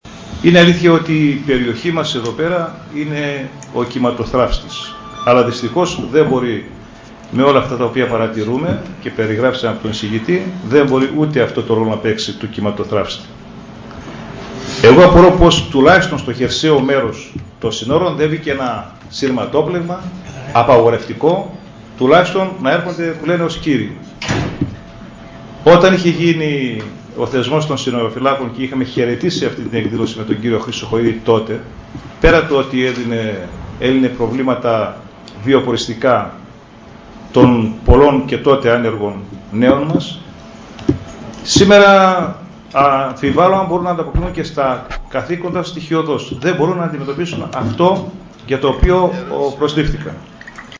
Άγγελος Παπαϊωάννου, δημ. συμβ., για το θέμα της λαθρομετανάστευσης – Δημ. Συμβ. Ορ/δας 14.09.2010